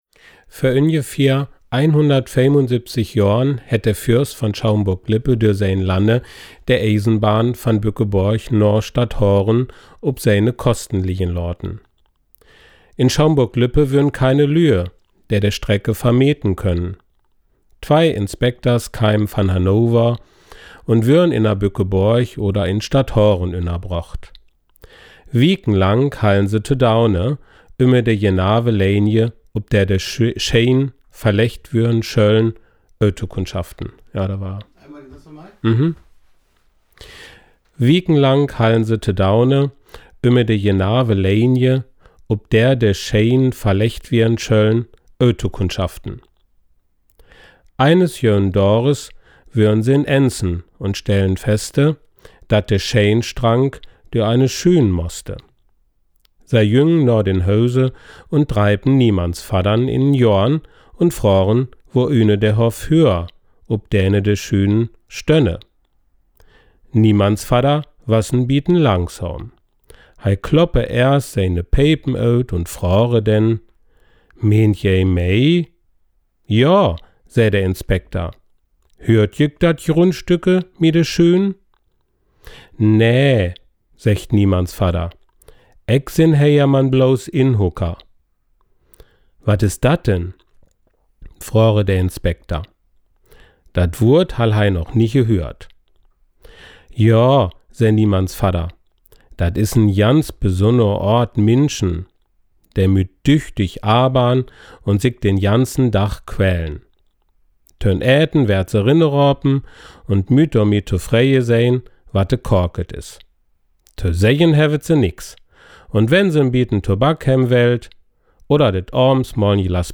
Südhorster Platt
Dai-Isenbahn-Südhorster-Platt.mp3